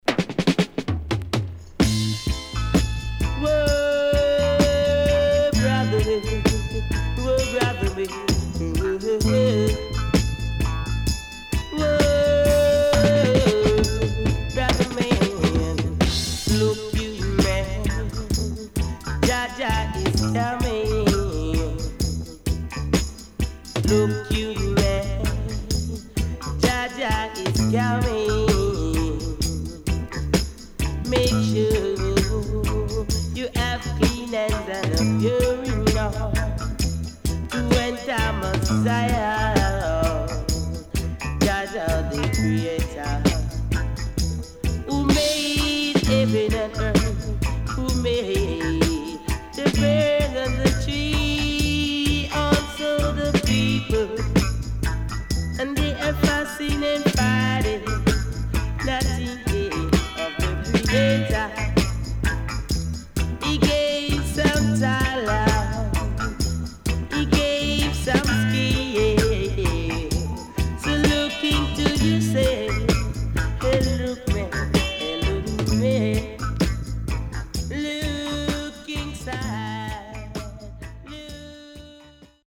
HOME > DISCO45 [DANCEHALL]  >  EARLY 80’s
Killer.Heavy Weight Track.W-Side Good
SIDE A:序盤小傷によりパチノイズ入ります。